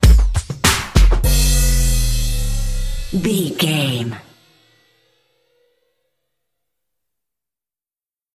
Ionian/Major
drum machine
synthesiser